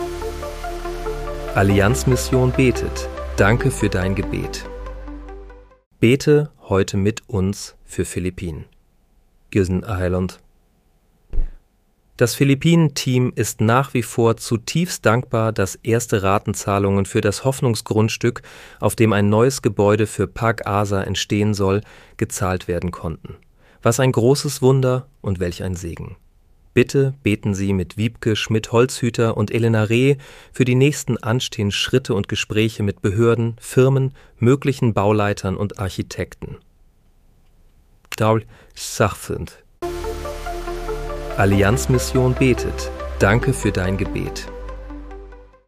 Bete am 28. März 2026 mit uns für Philippinen. (KI-generiert mit